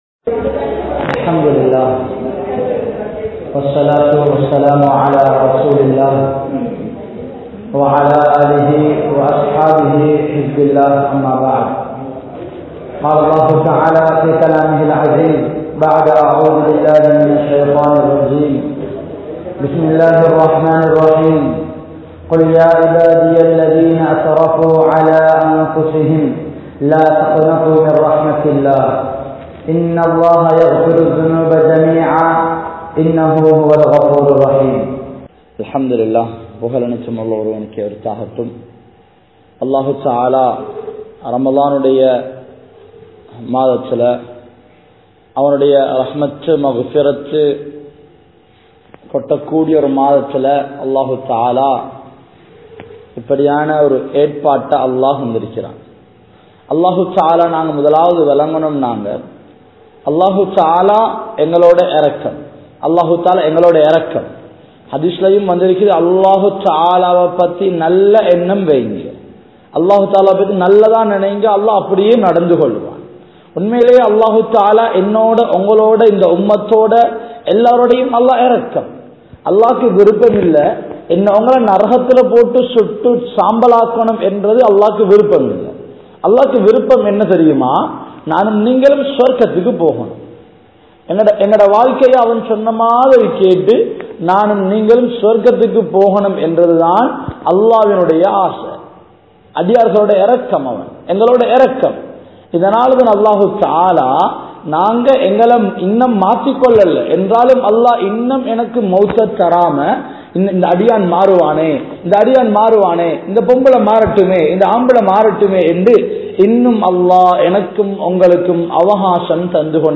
Anniya Aangalukku Munnaal Indraya Pengalin Aadaihal (அன்னிய ஆண்களுக்கு முன்னாள் இன்றைய பெண்களின் ஆடைகள்) | Audio Bayans | All Ceylon Muslim Youth Community | Addalaichenai
Matala, MC Road, Muhammadiya Masjidh